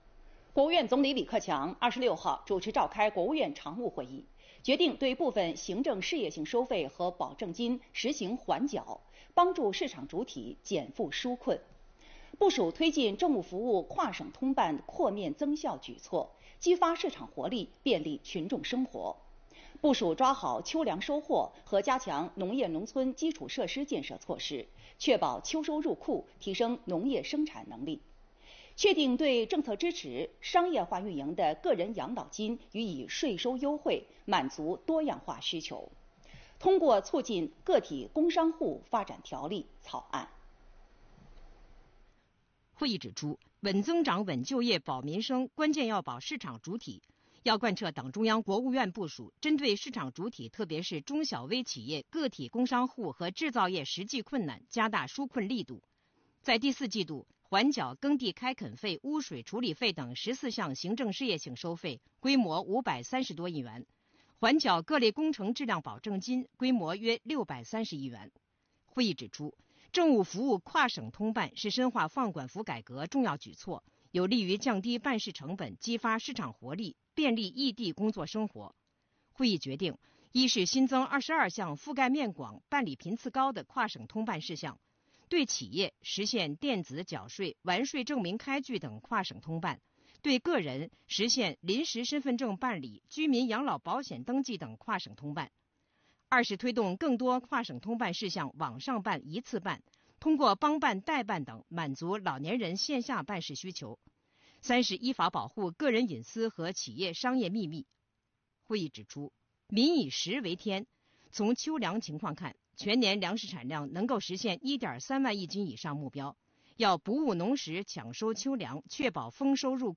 李克强主持召开国务院常务会议